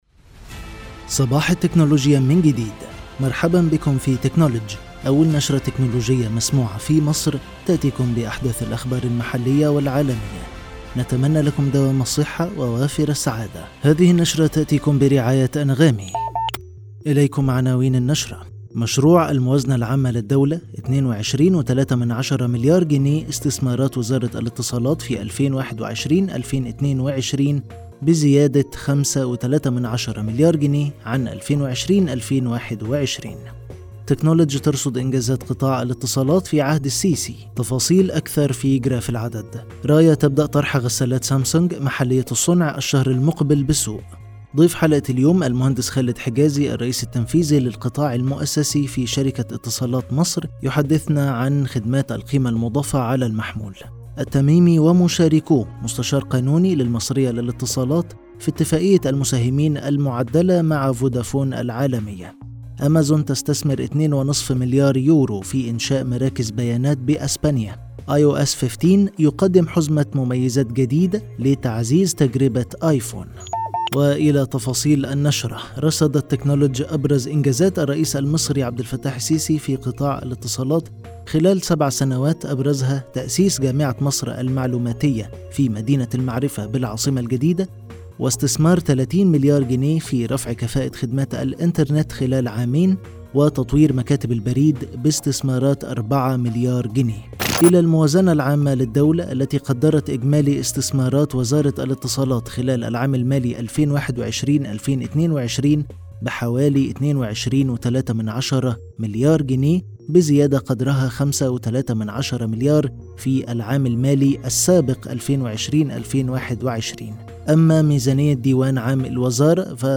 النشرة الأسبوعية